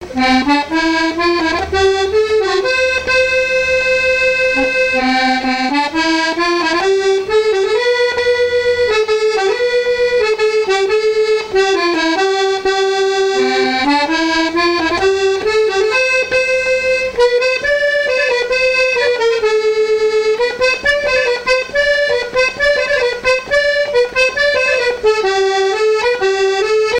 danse : marche
Pièce musicale inédite